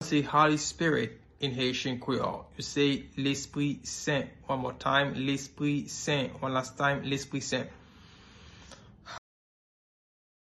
Pronunciation:
Listen to and watch “Lespri Sen” audio pronunciation in Haitian Creole by a native Haitian  in the video below:
13.How-to-say-Holy-Spirit-in-Haitian-Creole-–-Lespri-Sen-pronunciation-1-1.mp3